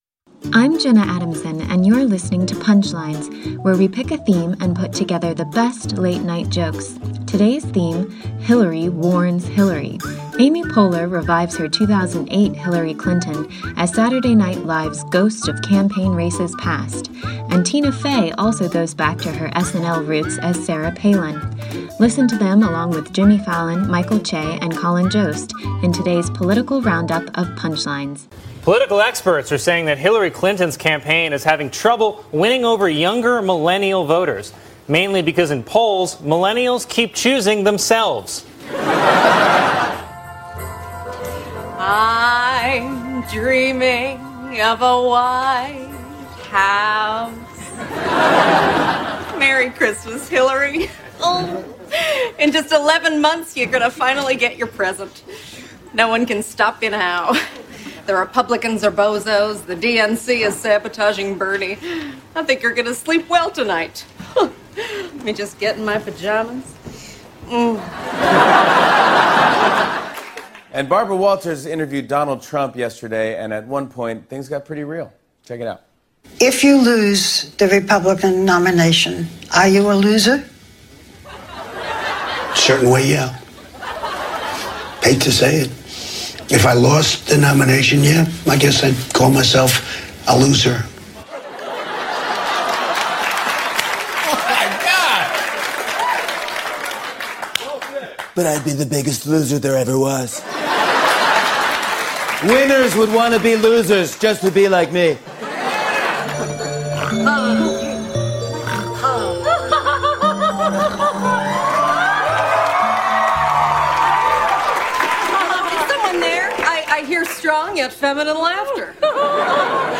The late-night comics on political campaigns, warnings and winning the White House.